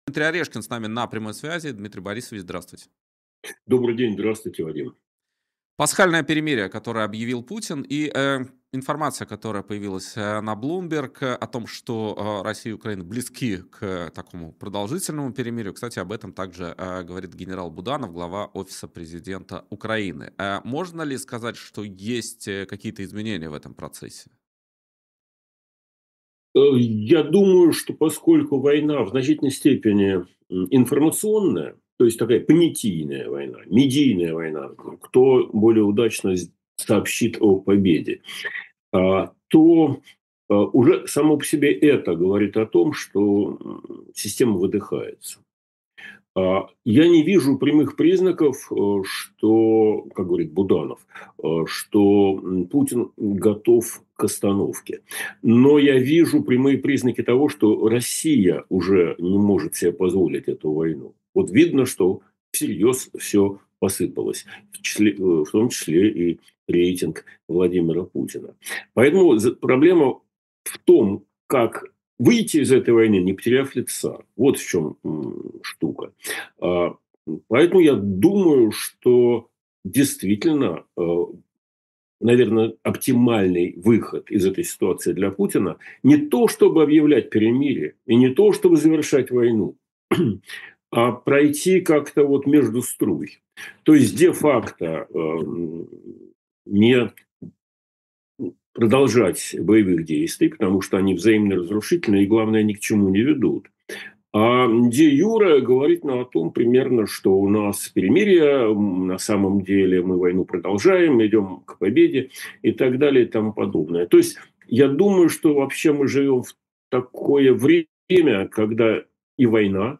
Эфир ведёт Вадим Радионов